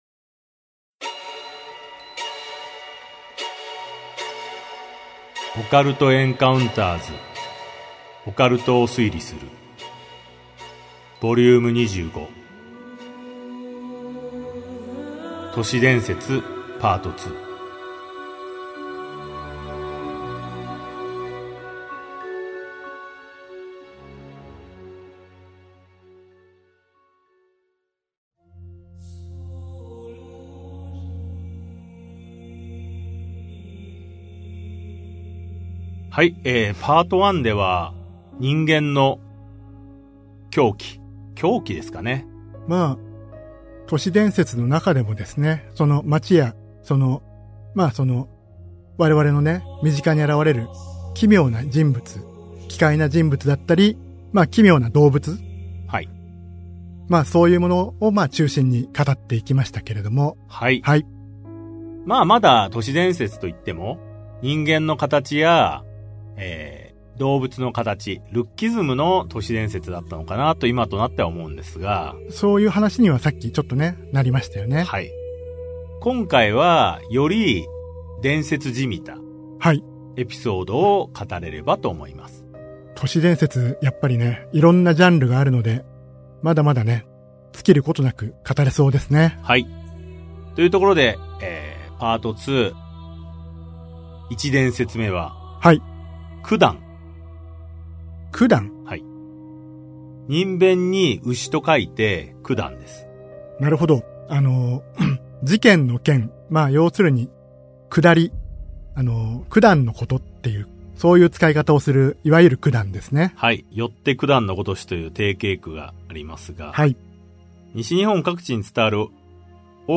[オーディオブック] オカルト・エンカウンターズ オカルトを推理する Vol.27 都市伝説 4